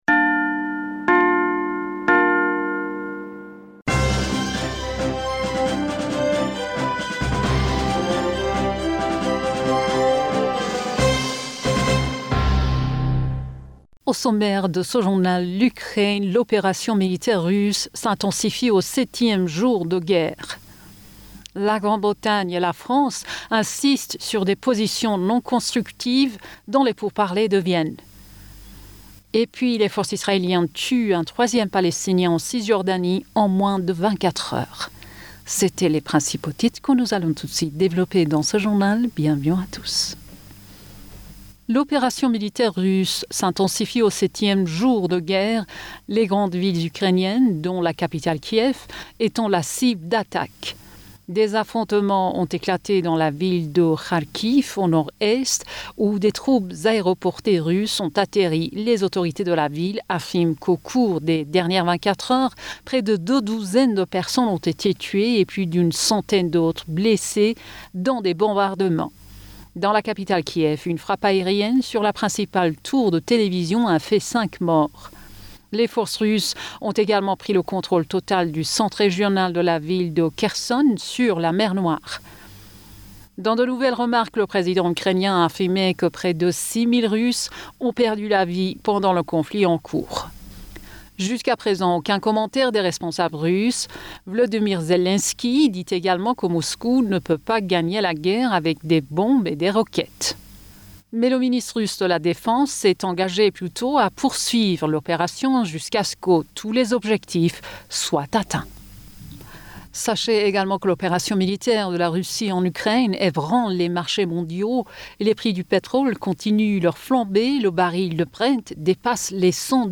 Bulletin d'information Du 02 Mars 2022